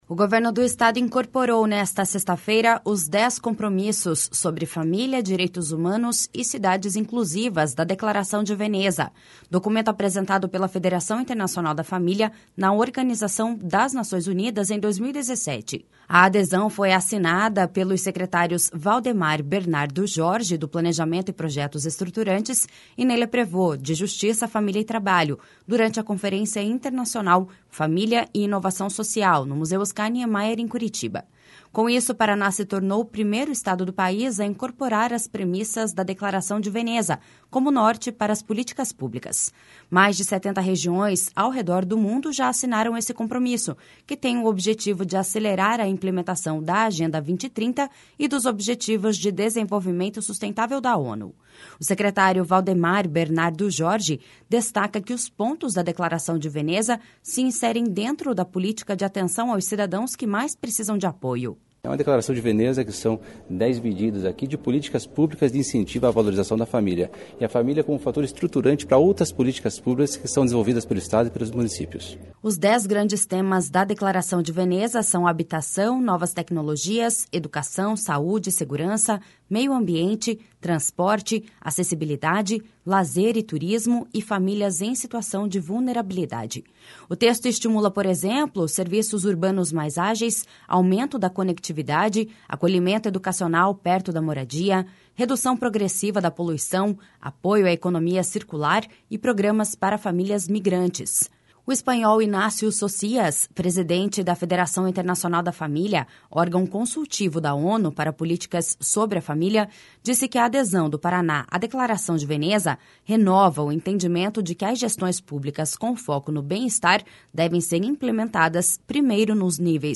O secretário Valdemar Bernardo Jorge destaca que os pontos da Declaração de Veneza se inserem dentro da política de atenção aos cidadãos que mais precisam de apoio.// SONORA VALDEMAR BERNARDO JORGE.//
Ela acrescentou que as declarações internacionais unem forças.// SONORA ÂNGELA VIDAL GANDRA MARTINS.//
O secretário de Justiça, Família e Trabalho, Ney Leprevost, reforçou que o Paraná olha para as famílias com o objetivo de diminuir os problemas sociais e melhorar o ambiente de desenvolvimento econômico, com base na gestão de ambientes de educação seguros e do pleno emprego.// SONORA NEY LEPREVOST.//